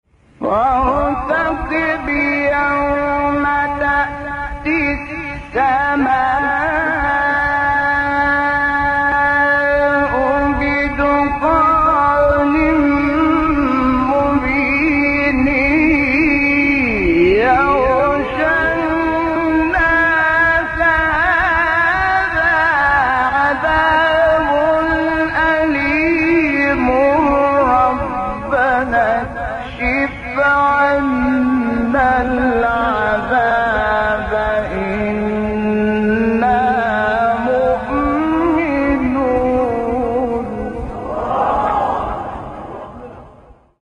سوره : دخان آیه: 10-12 استاد : شحات محمد انور مقام : بیات قبلی بعدی